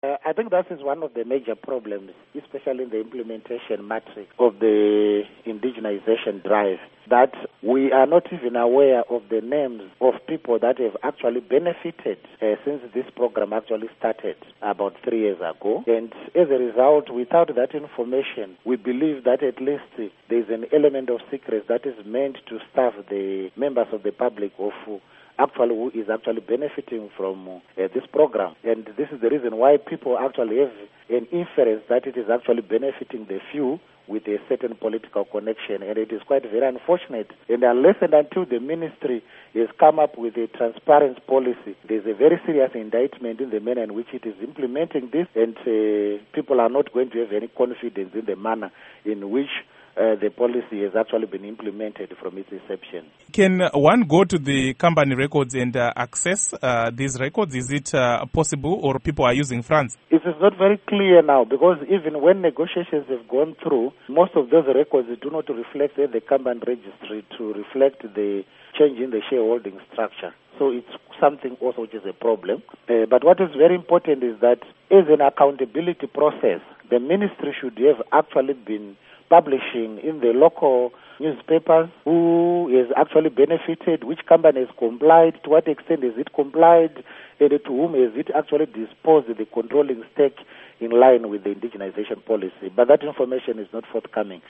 Interview Tongai Matutu